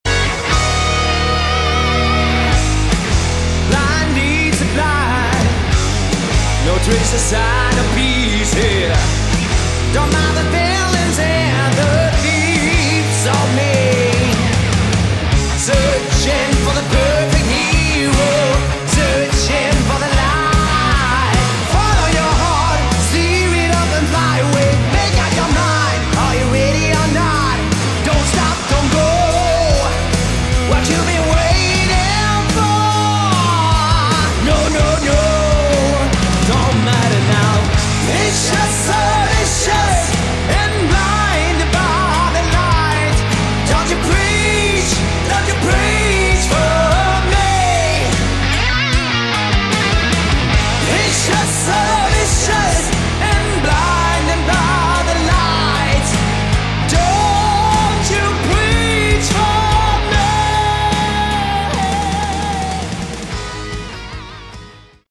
Category: Hard Rock / Melodic Metal
vocals
lead guitars
bass
drums